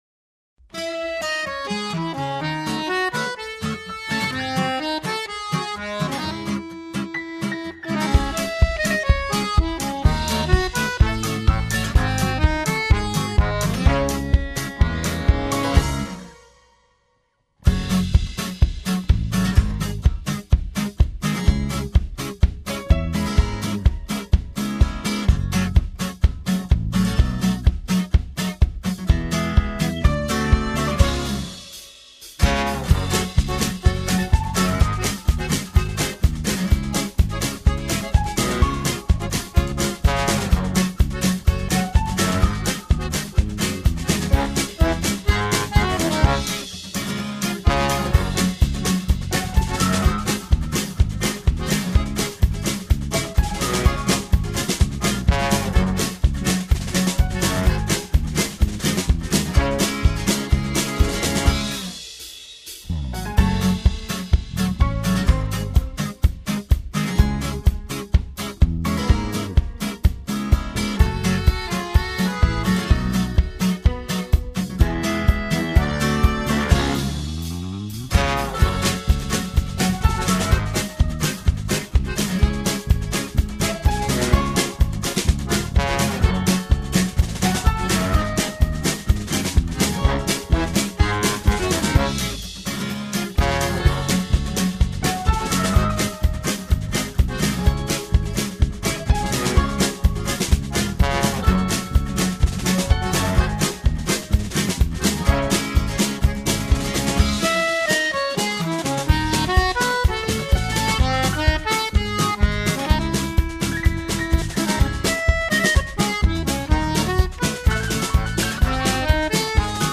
минусовка версия 172326